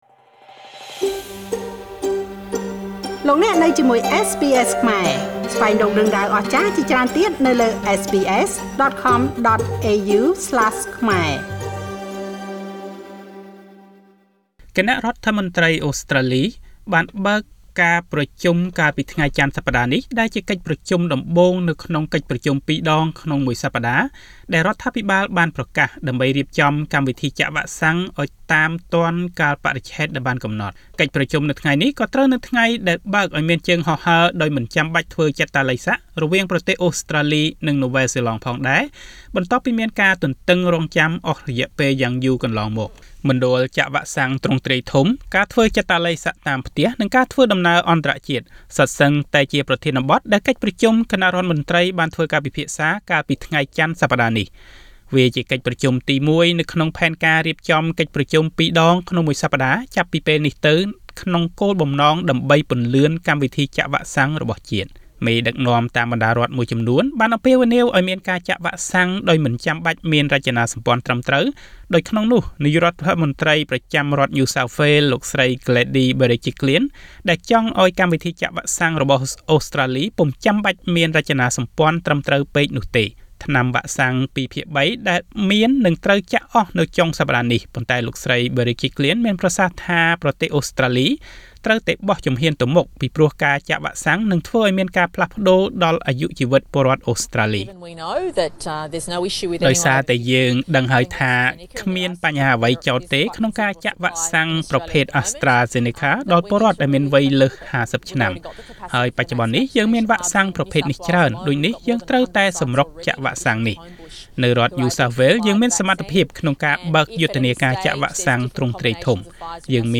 តទៅនេះសូមស្តាប់របាយការណ៍លម្អិតពីបច្ចុប្បន្នភាពនៃកម្មវិធីនេះដូចតទៅ ៖